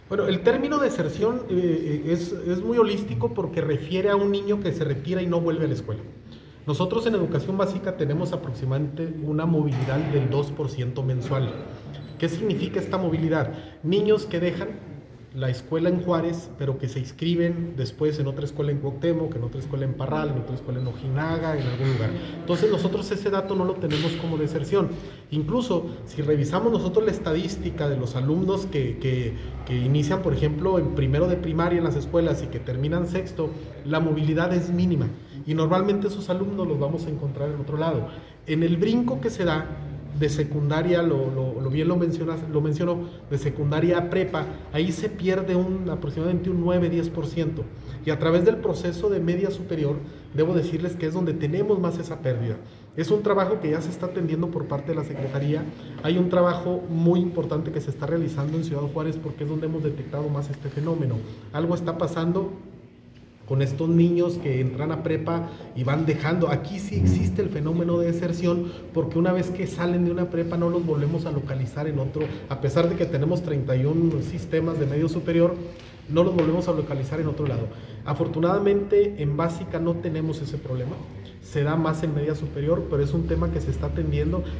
Interrogado sobre el panorama, el subsecretario de Educación Básica, Lorenzo Parga, mencionó que el término deserción resulta ambiguo para describir el fenómeno de abandono escolar, pues abarca en ocasiones la movilidad.